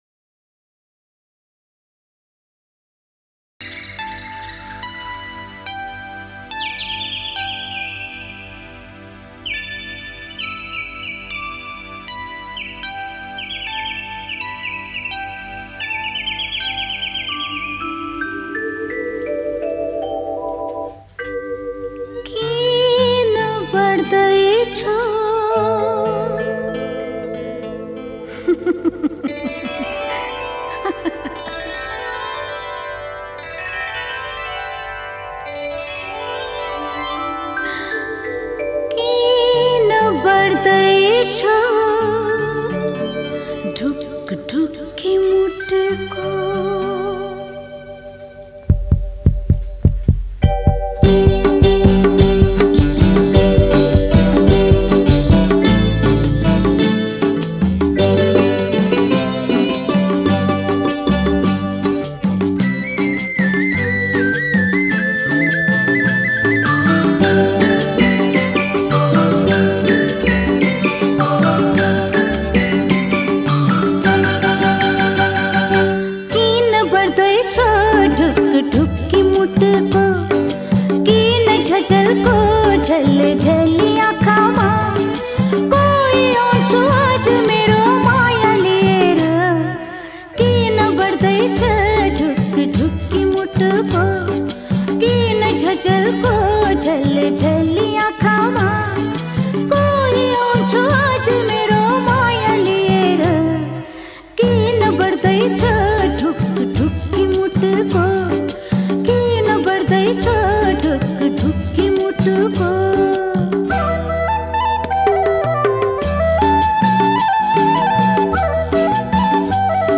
Nepali Movie Song